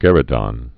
(gĕrĭ-dŏn, gā-rē-dôɴ)